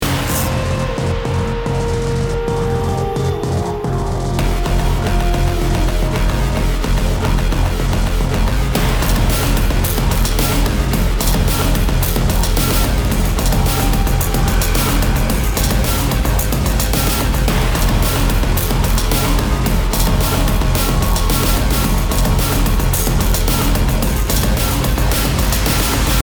BPM 110